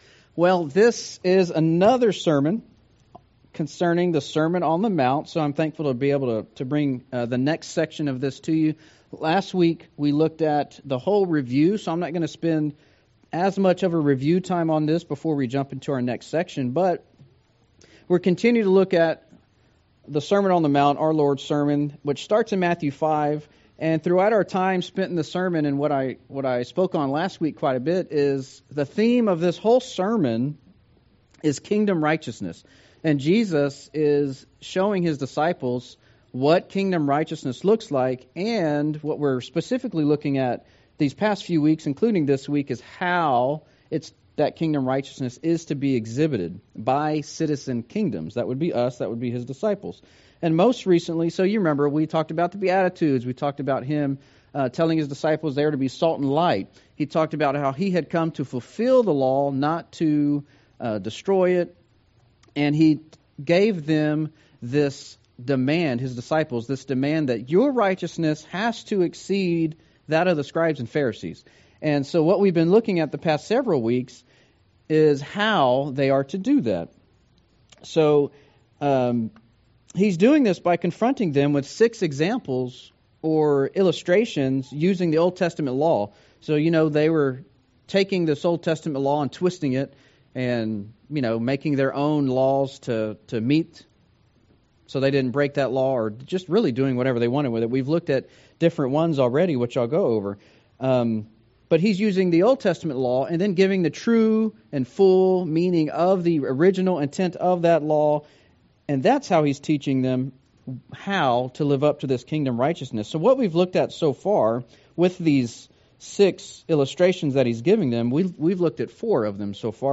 Sermon on the Mount Passage: Matthew 5:38-42 « Righteousness of the Kingdom